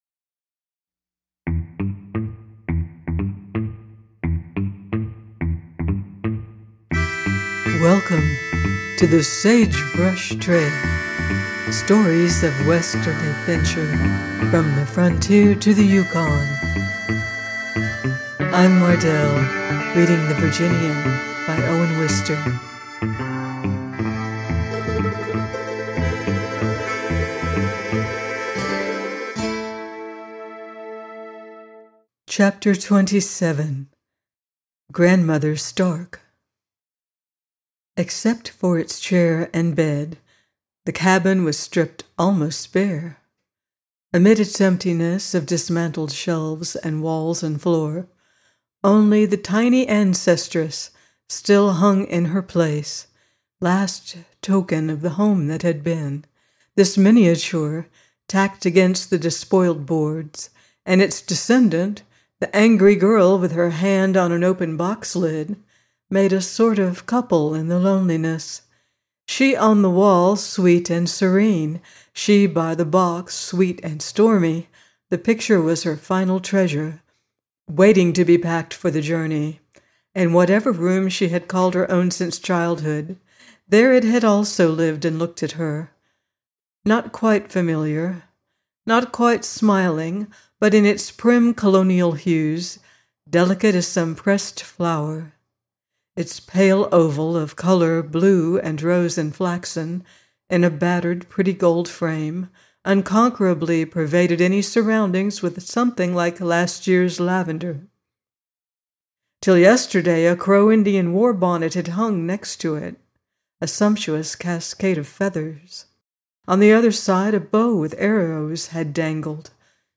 The Virginian - by Owen Wister - audiobook